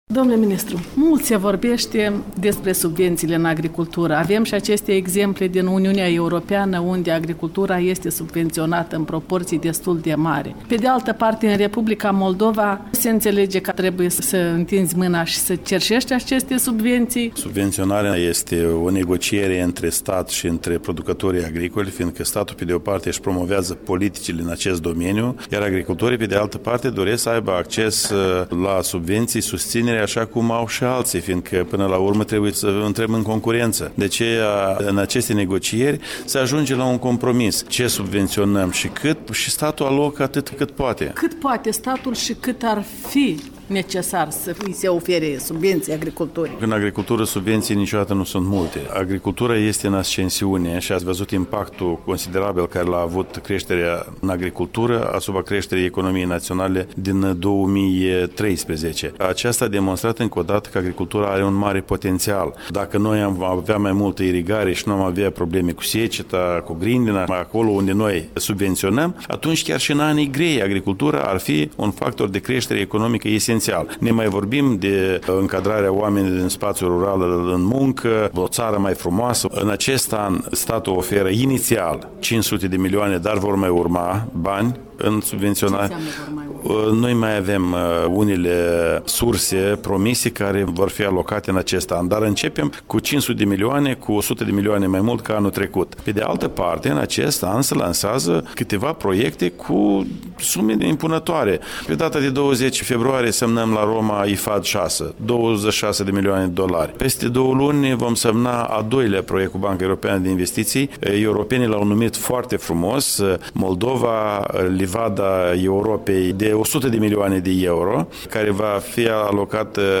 în dialog cu ministrul agriculturii Vasile Bumacov